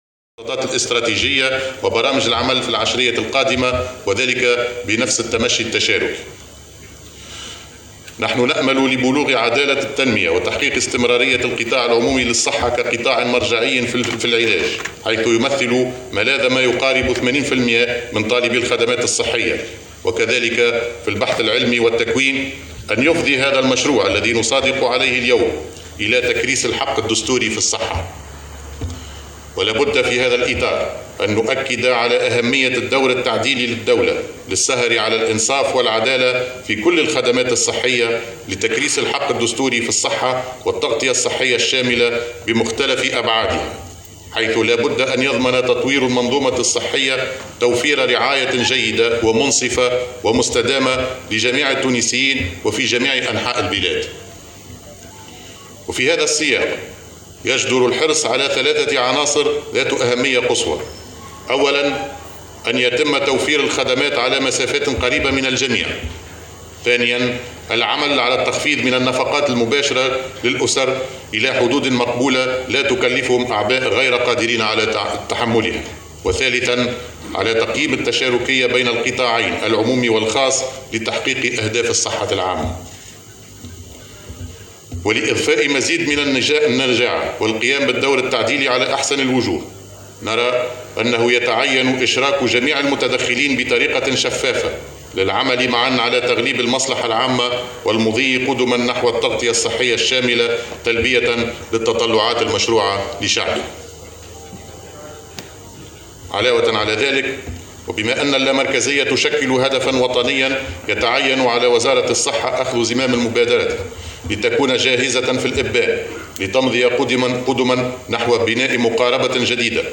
أشرف رئيس الحكومة هشام مشيشي اليوم الاربعاء على موكب المصادقة على السياسة الوطنية للصحة 2030 بمناسبة يوم الصحة العالمي 2021 بتنظيم من وزارة الصحة، شدد خلاله على ضرورة توفير رعاية صحّية جيّدة لجميع التّونسيين.